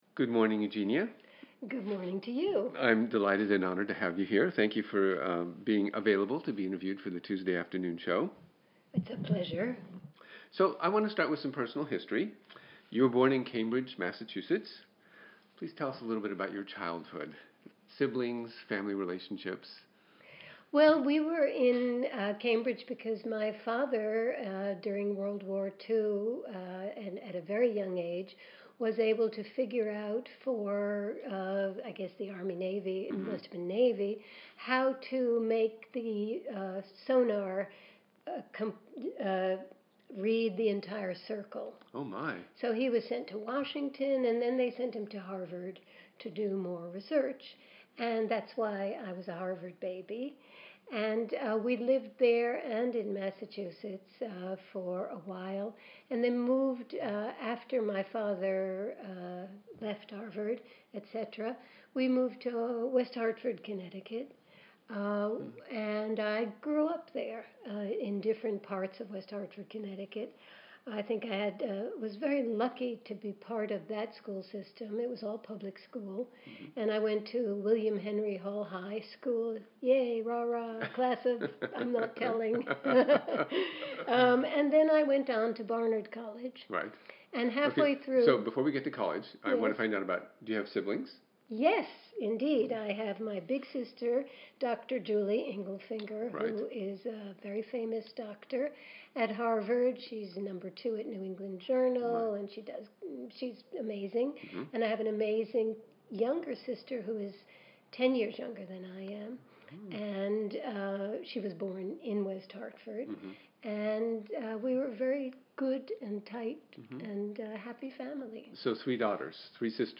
Eugenia Zukerman, flutist and Classics on Hudson artistic director, discusses her career and lifetime of volunteer work. (Audio)
Interviewed